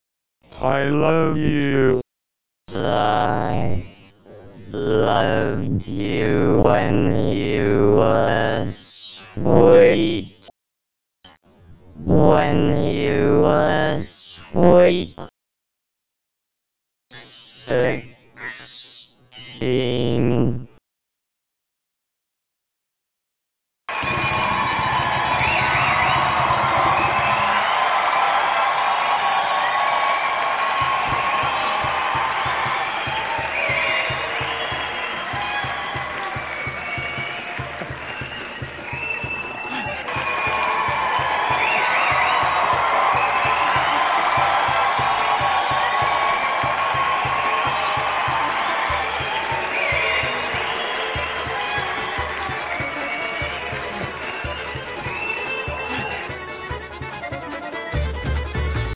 There would be two teams, one from the US, and one from the UK, each consisting of four players. 2. Each player would go out and find one minute's worth of ambient sound and record it. 3.
Each of the team members would then take their chosen sound and go and find or create a suitable cover. 7. There are no limits to how the cover could be achieved except that no part of the original could be used. 8.
cover57342.mp3